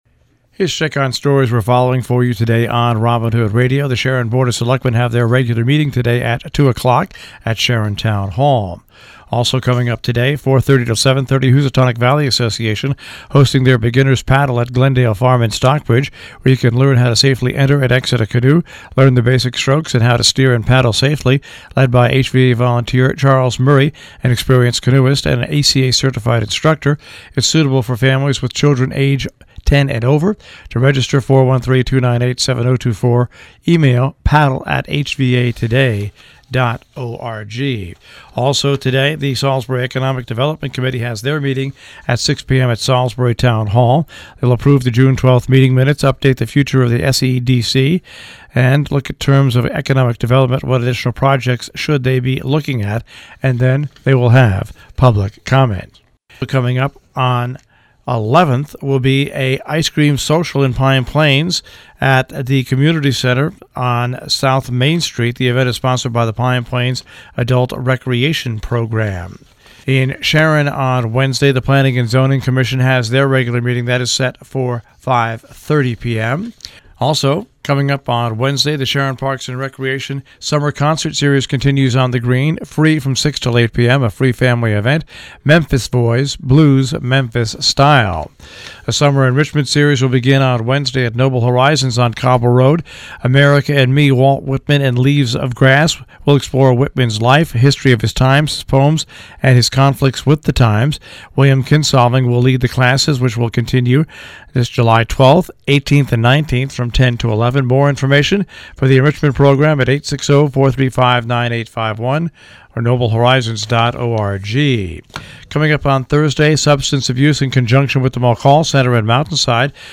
WHDD Breakfast Club News